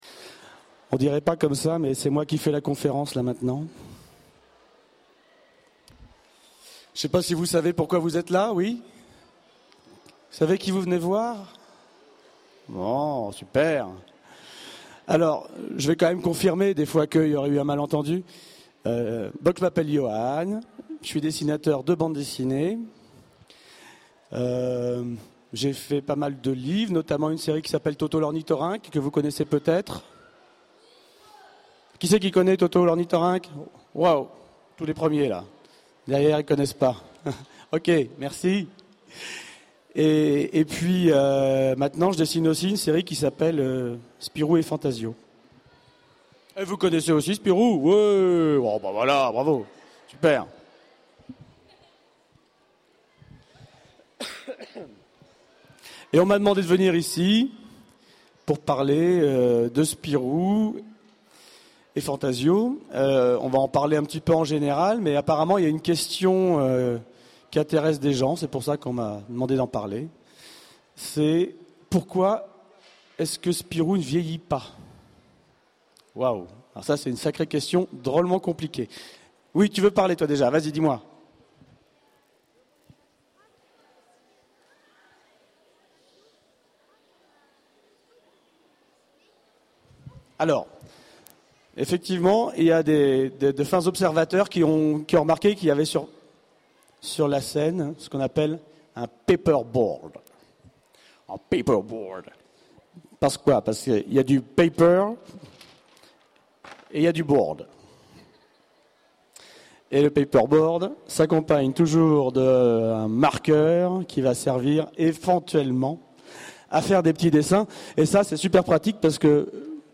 - le 31/10/2017 Partager Commenter Utopiales 12 : Conférence